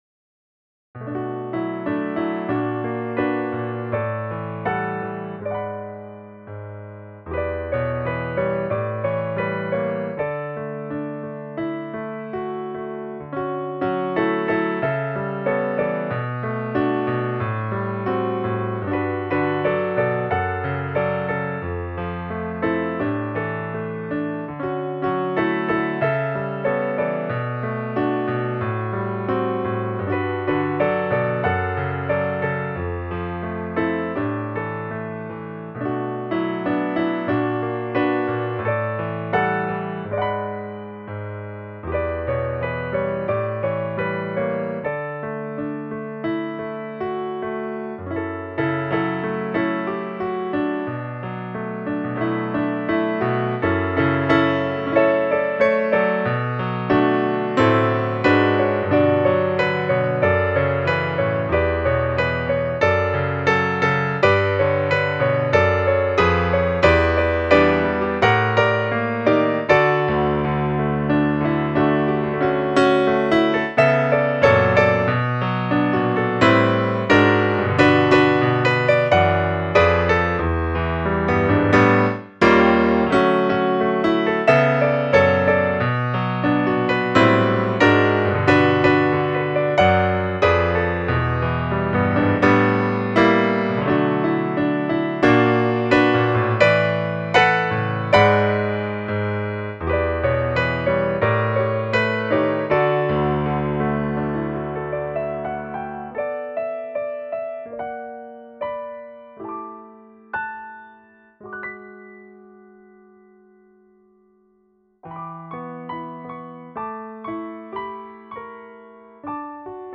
Piano Arrange
しかし、ピアノは難しい…
今回、丁寧に弾いてみたつもりだけど、
最初のパートはもっと抑揚をつけても良かったし、
中盤はもっとテンポを維持できるようにしないと…
演奏は当然新しい鍵盤。